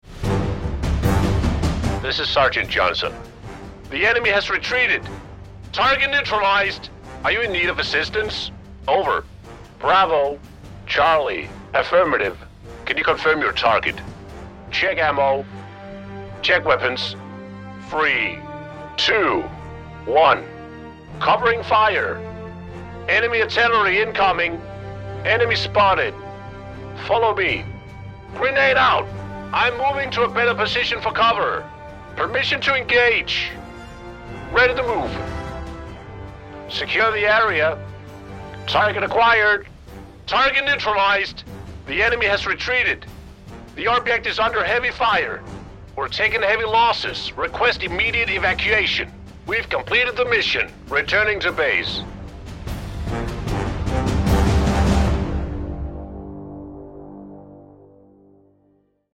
commando-calls-sfx-pack-preview.mp3